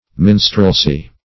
Minstrelsy \Min"strel*sy\, n.